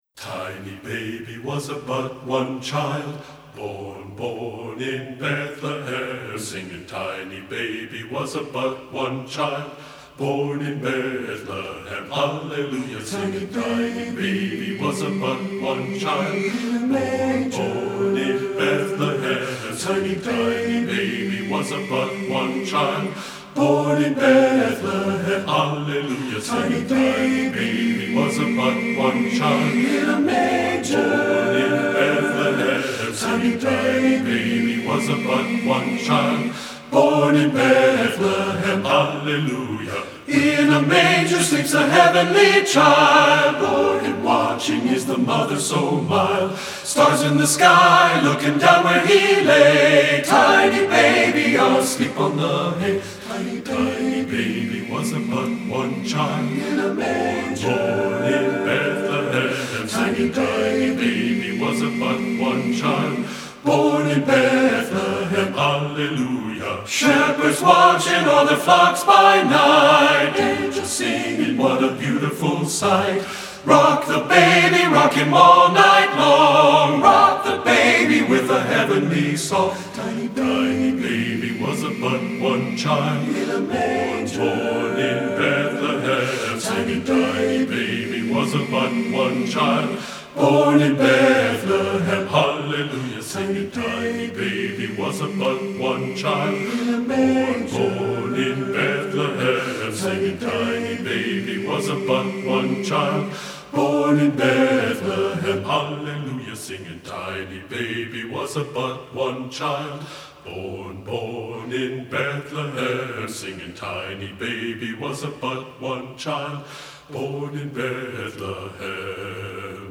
secular choral
TTBB, a cappella (TTBB recording)